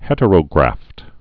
(hĕtə-rō-grăft)